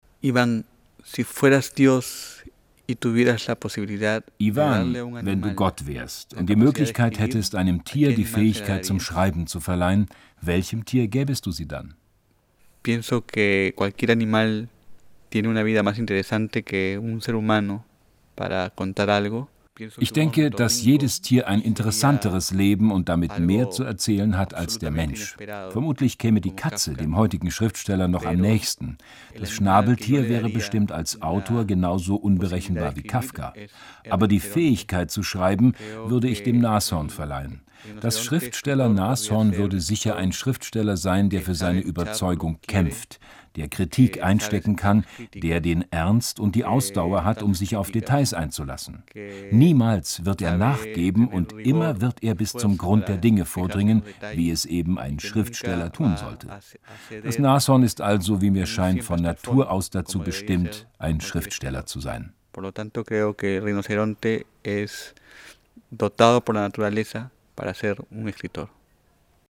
Er hat über 90 Schriftsteller gebeten, sich selbst eine Frage zu stellen und sie dann zu beantworten.
Deutschlandradio Kultur sendet die Fragen und Antworten als Mini-Selbstgespräche in der Zeit vom 8.10. bis 14.10.07 jeweils in "Fazit am Abend" und in "Fazit" - parallel zur Frankfurter Buchmesse.